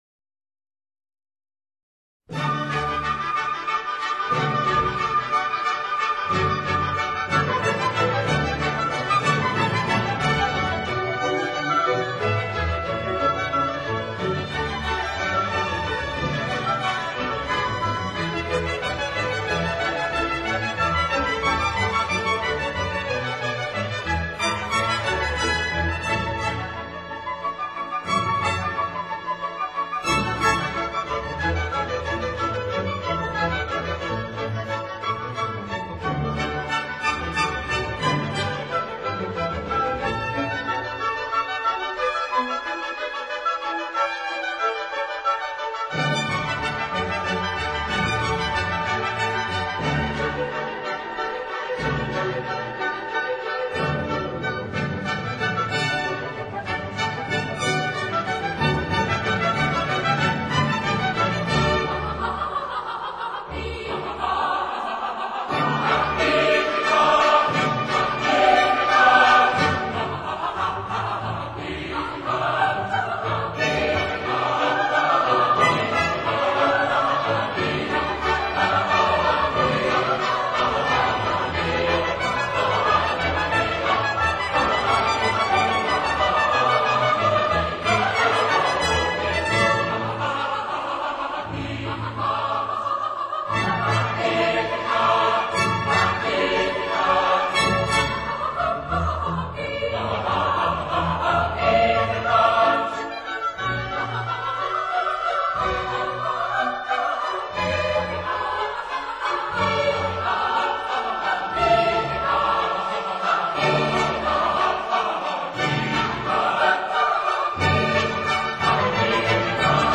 八、圣母赞美曲Magnificat）